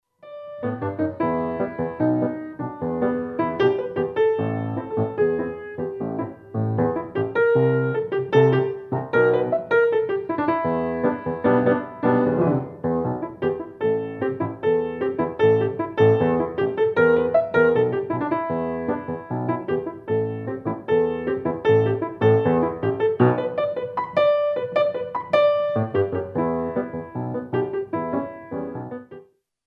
QUICK